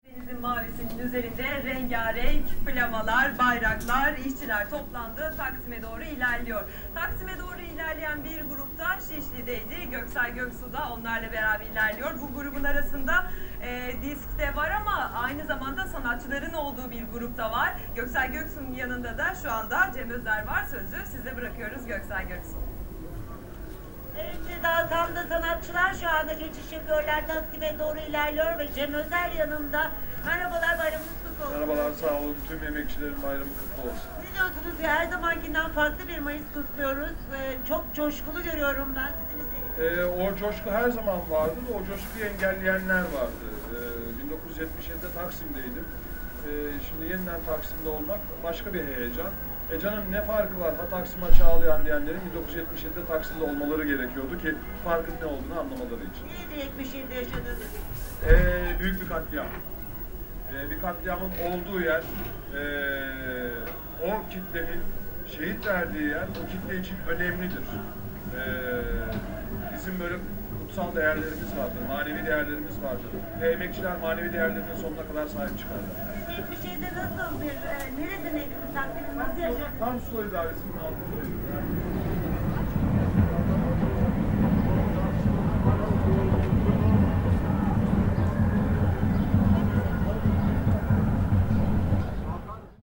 The empty road
There isn’t a single car or bus on the road. No rolling tyres, no murmur, no beeping, no accelerating, nothing.
I turn on the TV, there’s a live broadcast, a reporter among the marching crowds interviewing Cem Özer who was present also at the 1977 Labour day march.
At the end of this recording I direct the microphone out of the window. Now there is the crowd on its way marching uphill to the square.